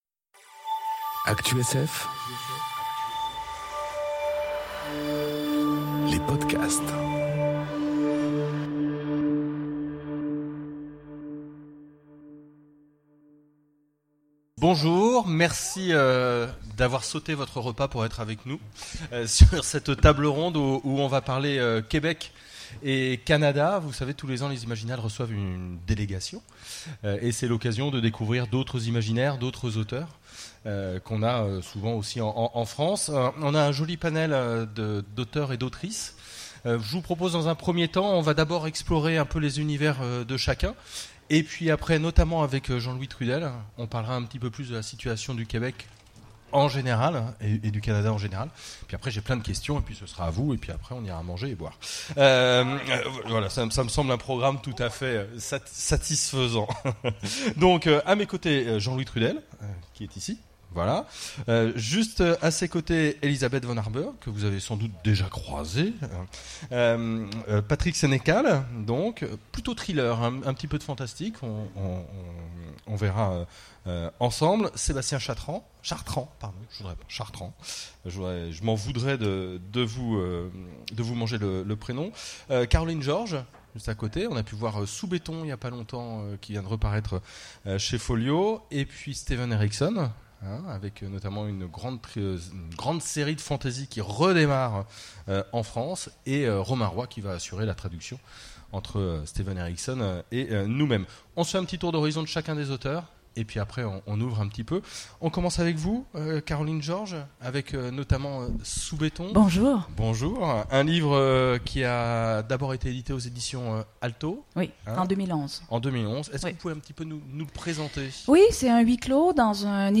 Conférence Les auteurs canadiens... Ils nous en mettent plein la vue ! enregistrée aux Imaginales 2018